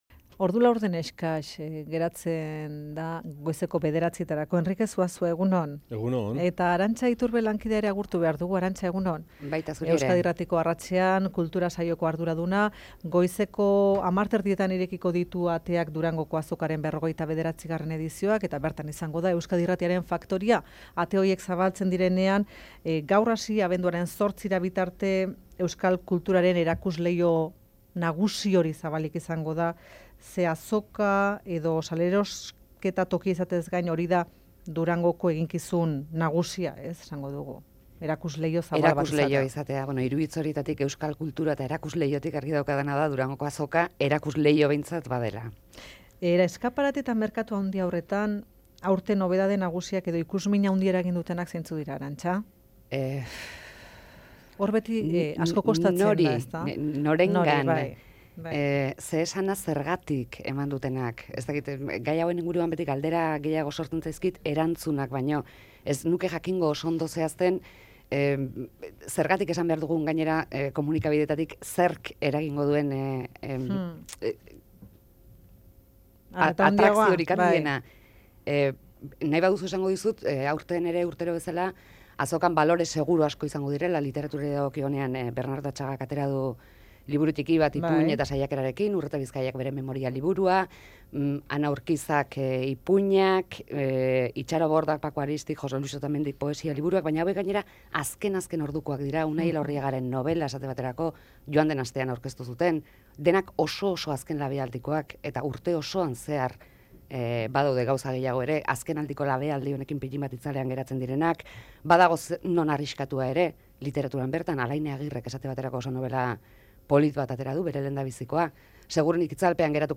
Durangoko azokaren ereduaz solasaldia